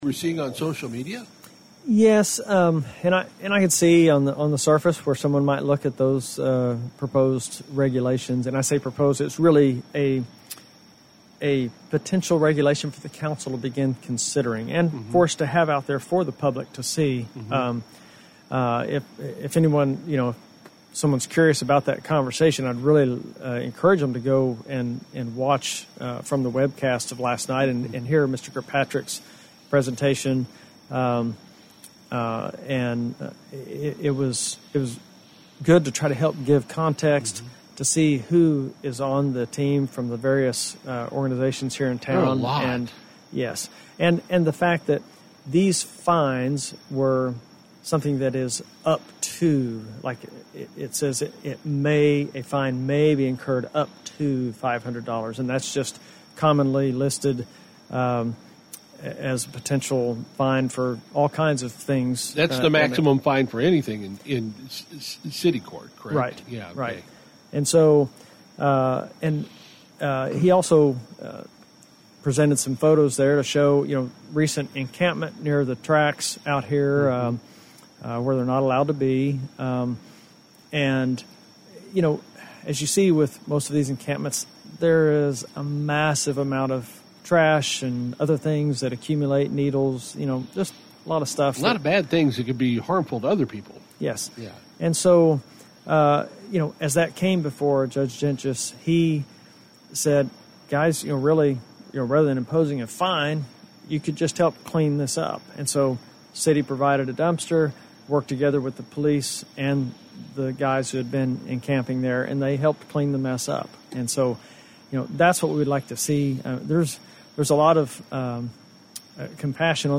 Appearing on KWON's CITY MATTERS, Bartlesville City Councilor Larry East was our guest to review Monday's city council meeting.